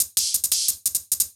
Index of /musicradar/ultimate-hihat-samples/175bpm
UHH_ElectroHatD_175-05.wav